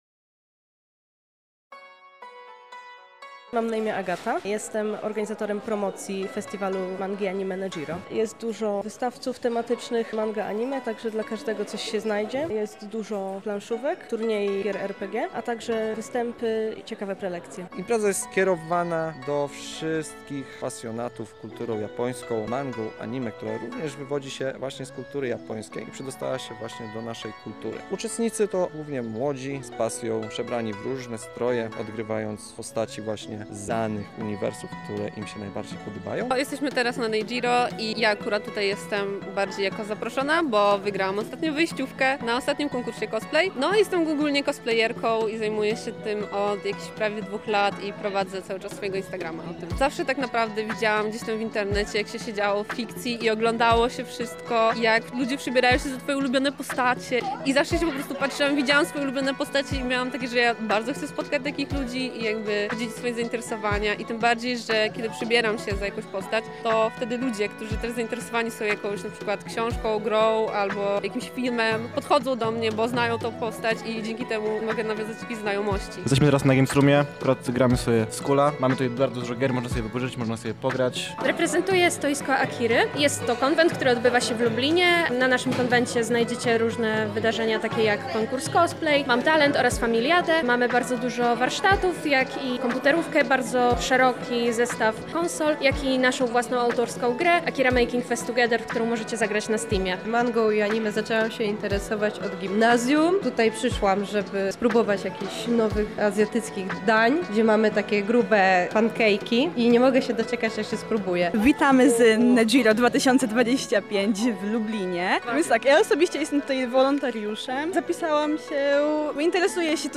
W miniony weekend w Lublinie odbył się festiwal Nejiro.
Nejiro – relacja